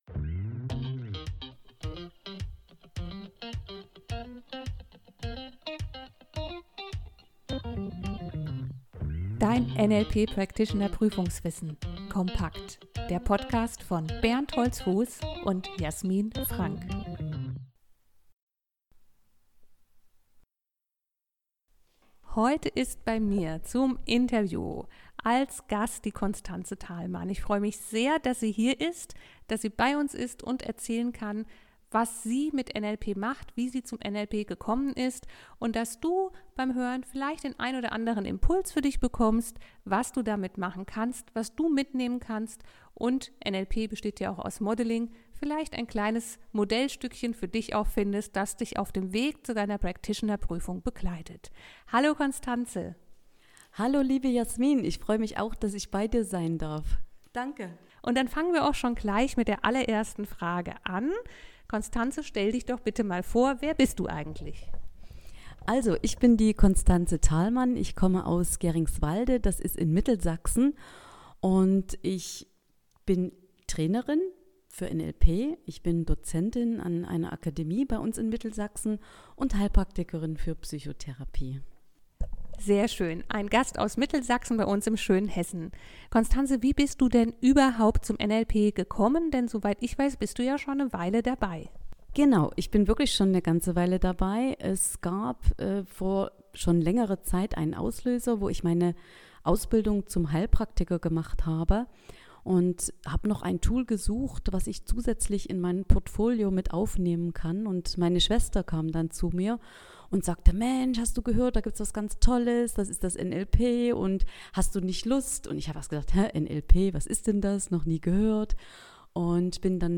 NLP Interview